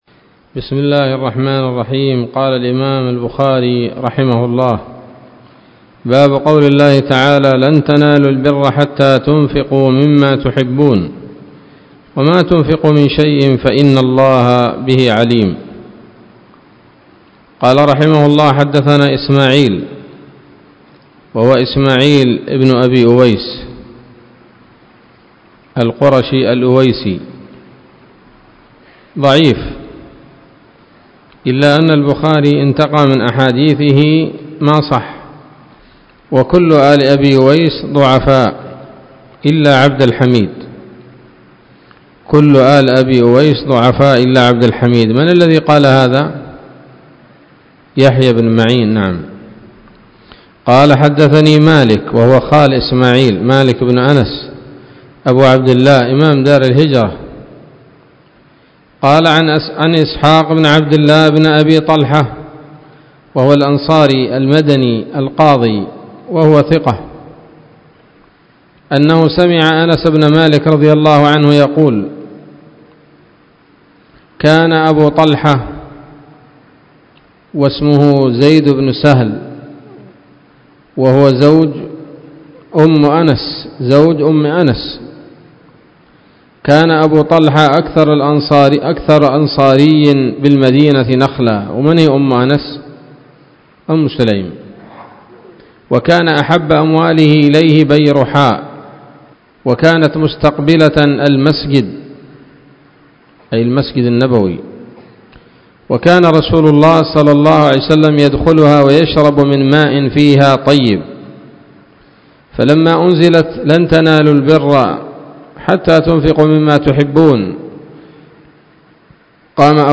الدرس التاسع والأربعون من كتاب التفسير من صحيح الإمام البخاري